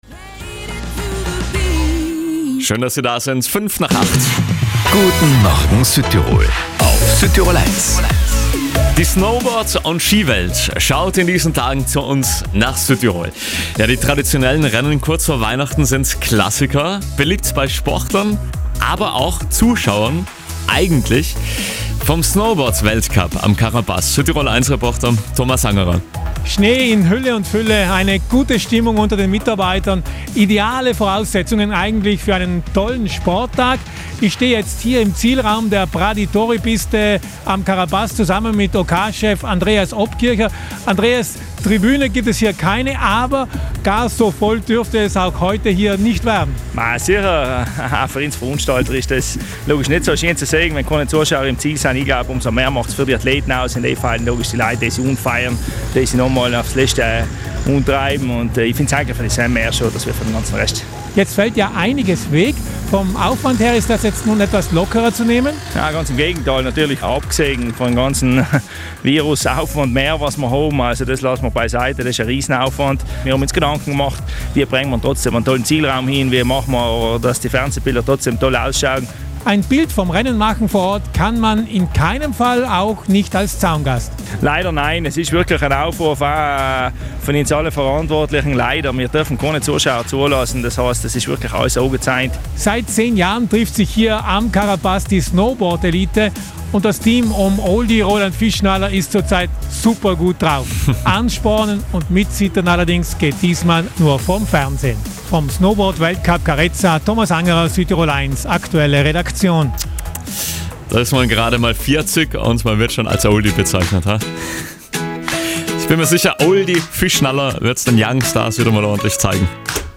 im Zielraum am Karerpass